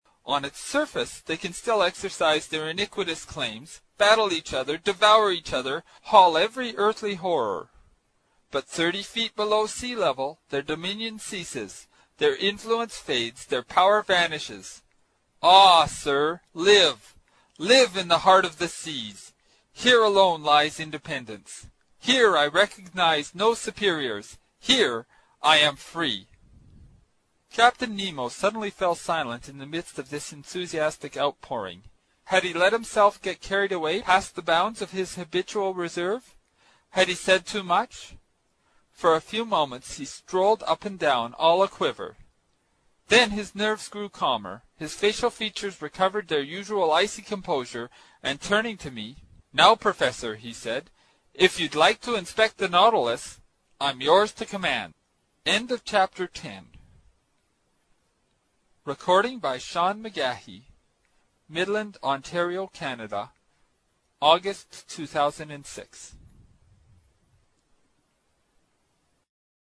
英语听书《海底两万里》第149期 第10章 水中人(20) 听力文件下载—在线英语听力室
在线英语听力室英语听书《海底两万里》第149期 第10章 水中人(20)的听力文件下载,《海底两万里》中英双语有声读物附MP3下载